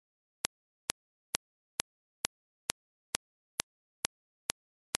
Impulses.mp3